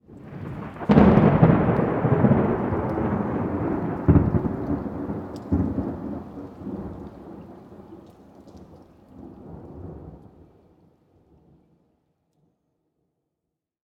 thunderfar_23.ogg